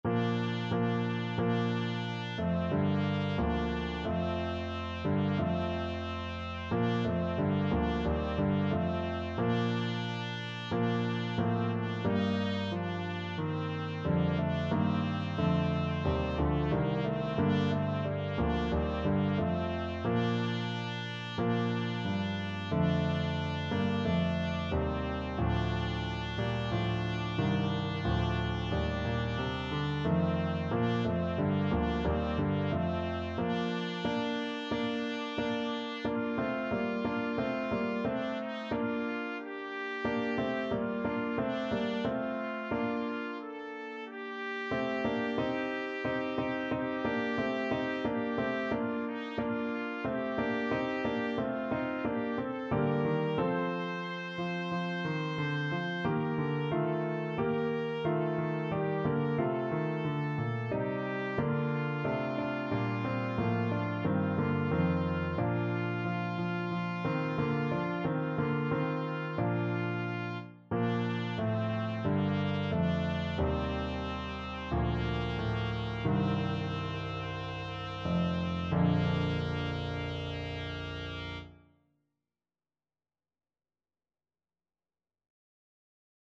Classical Rachmaninoff, Sergei Bogoroditse Devo from the Vespers Trumpet version
Bb major (Sounding Pitch) C major (Trumpet in Bb) (View more Bb major Music for Trumpet )
4/4 (View more 4/4 Music)
Classical (View more Classical Trumpet Music)